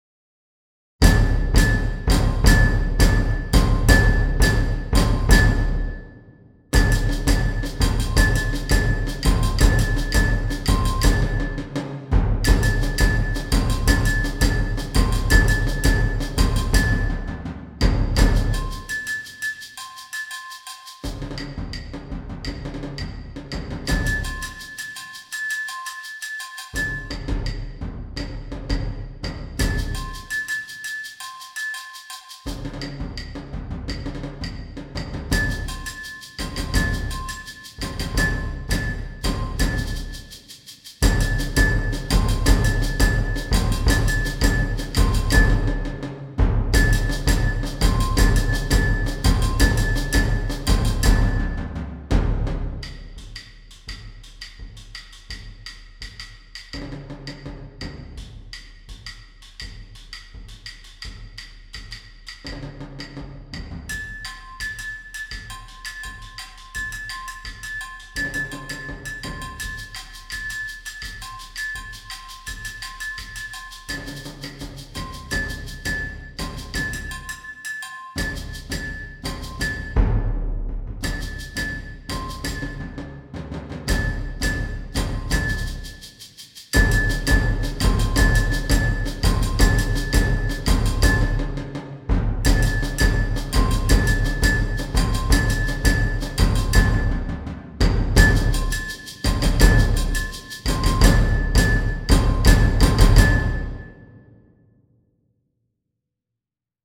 Percussion Ensemble
Using some Latin percussion influence